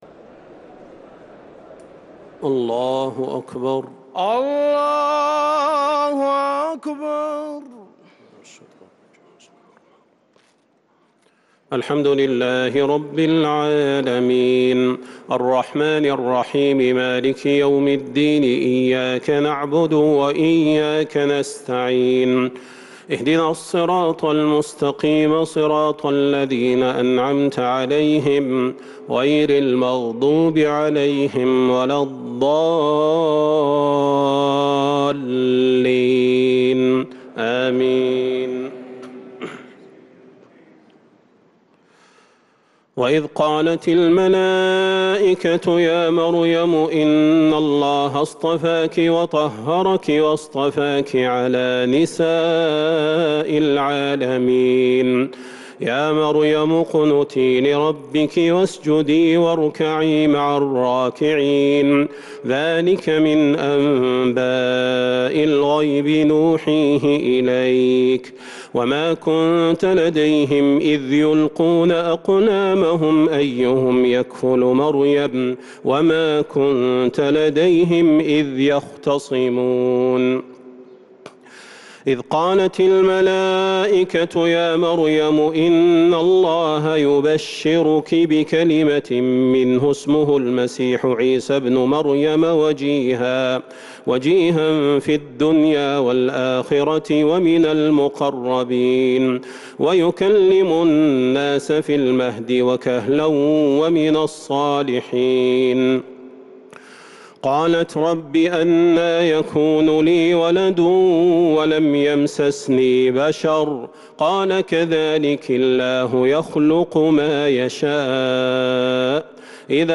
صلاة التراويح ليلة 4 رمضان 1443 للقارئ صلاح البدير - التسليمة الأخيرة صلاة التراويح
تِلَاوَات الْحَرَمَيْن .